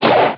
HHGthrow.mp3